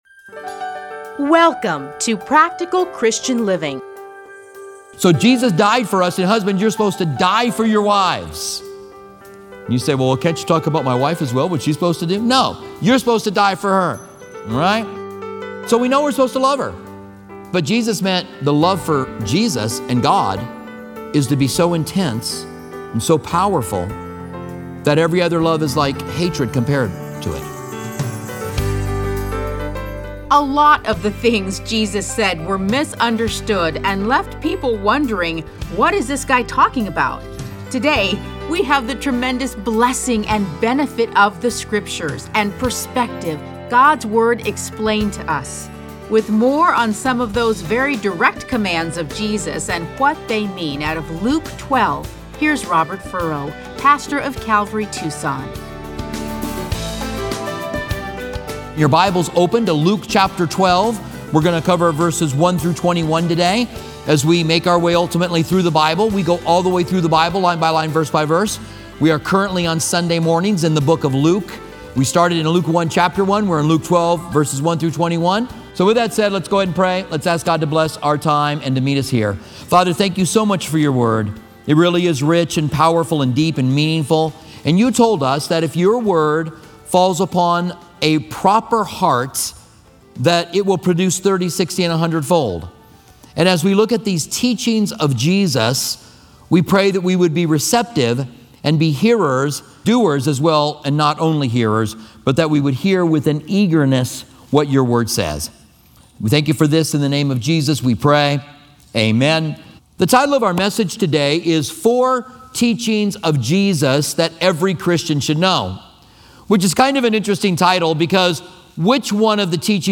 Listen to a teaching from Luke 12:1-21.